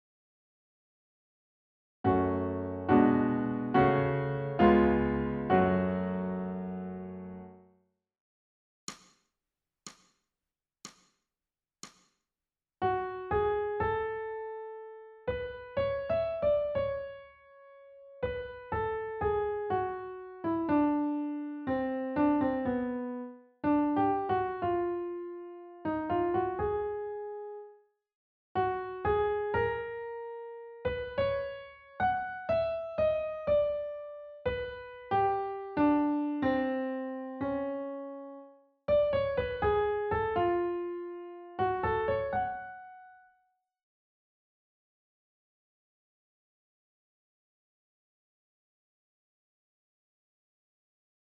ソルフェージュ 聴音: 2-1-26